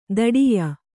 ♪ daḍiya